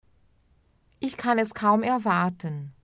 Veja abaixo vários exemplos com áudio para treinar a pronúncia e aumentar ainda mais o seu vocabulário em alemão.